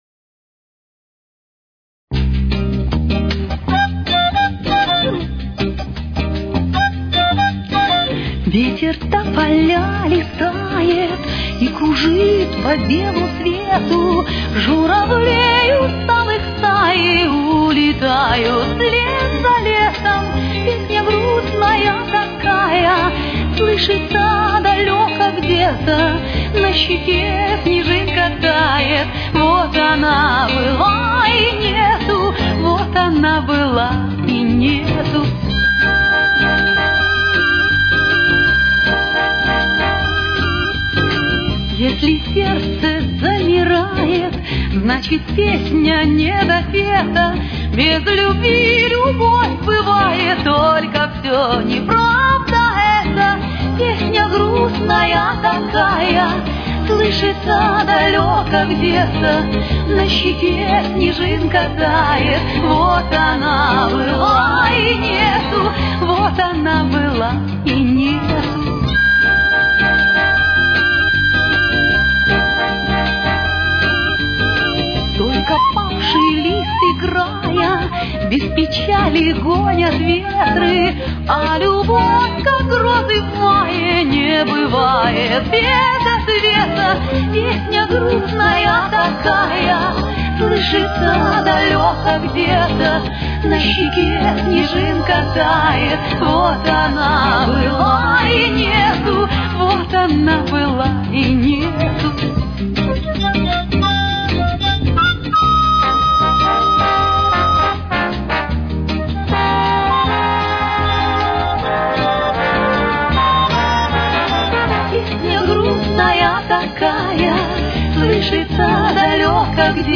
Темп: 162.